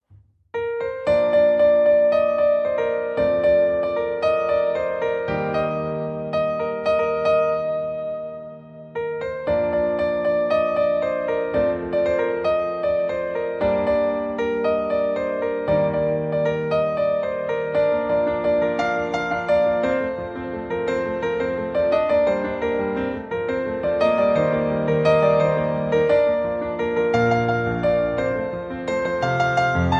• Key: Bb Minor
• Instruments: Piano solo
• Genre: Pop, Film/TV